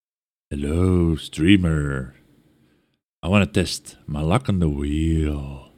PLAY spinning wheel sound
wheel.mp3